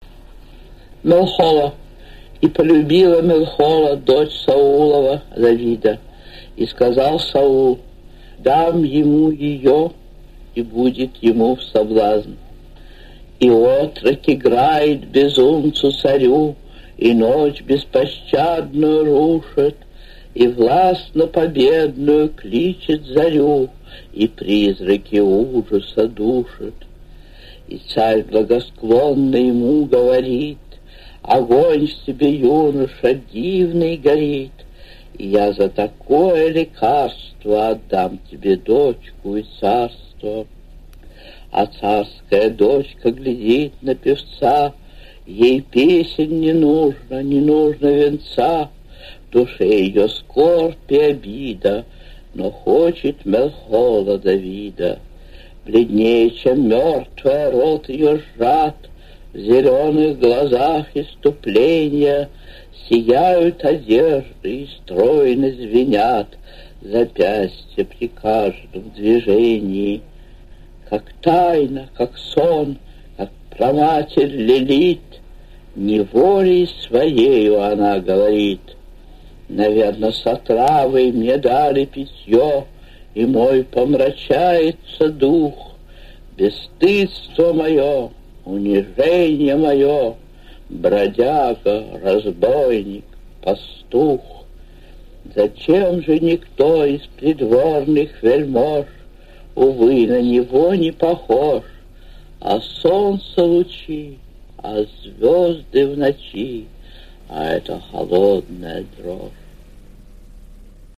2. «Анна Ахматова – Мелхола (читает автор)» /